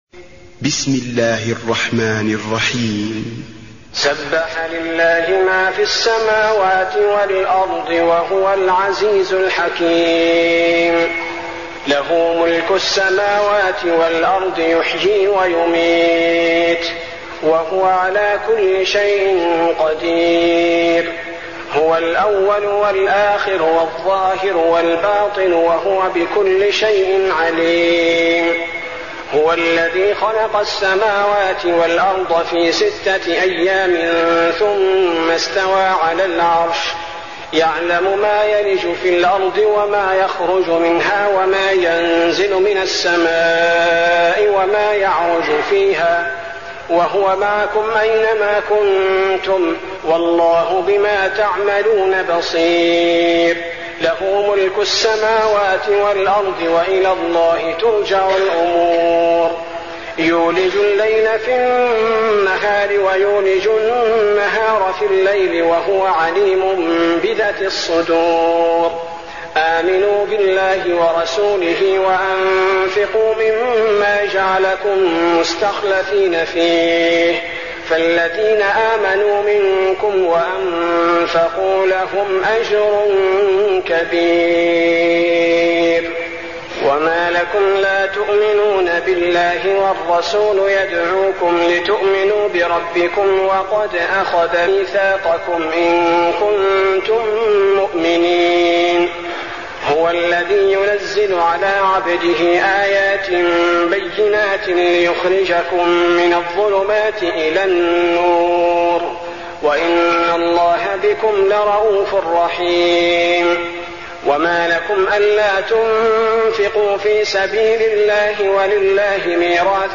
المكان: المسجد النبوي الحديد